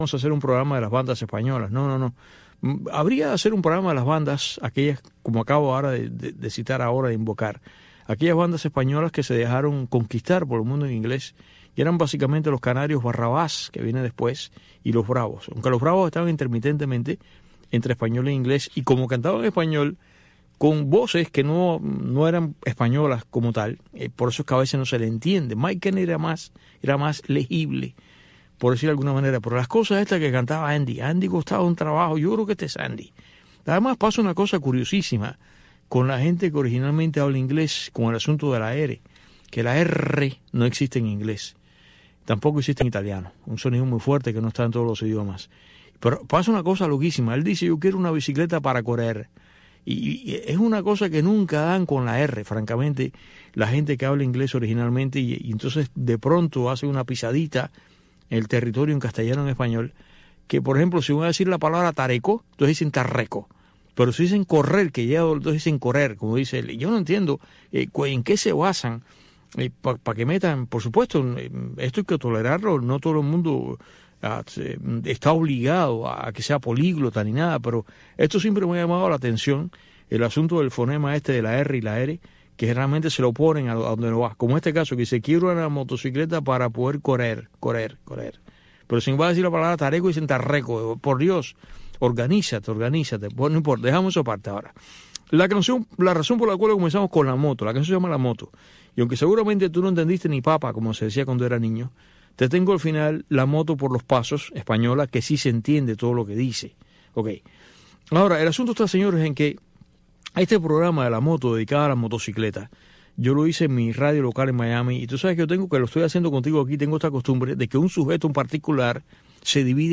conecta cada día con sus invitados en la isla en este espacio informativo en vivo